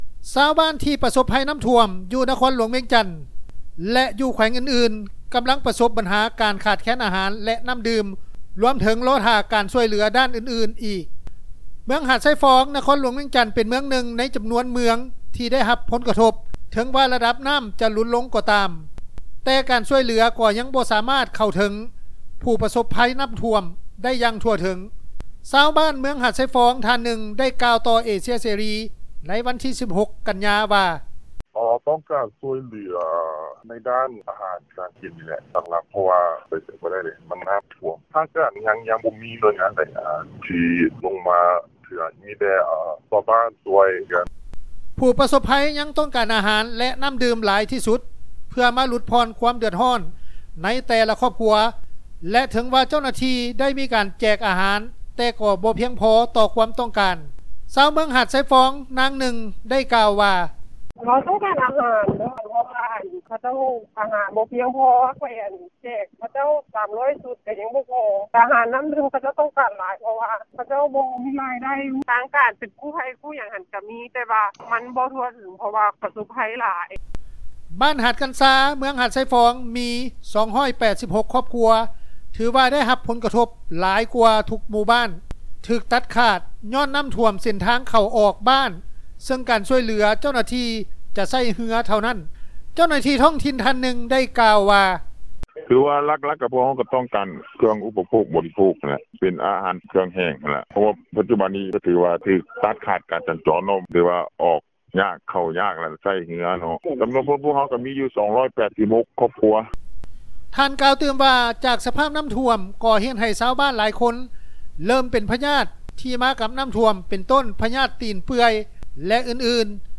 ຊາວບ້ານເມືອງຫາຊາຍຟອງ ທ່ານນຶ່ງ ໄດ້ກ່າວຕໍ່ເອເຊັຽເສຣີ ໃນວັນທີ 16 ກັນຍາ ວ່າ: